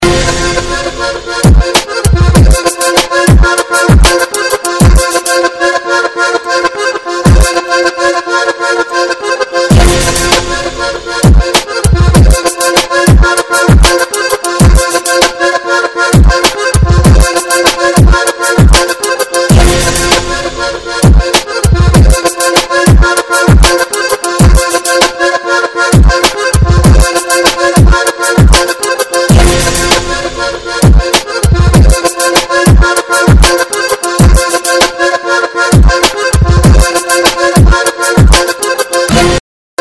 House
/64kbps) Описание: Прикольная гармошка в стиле клубняка!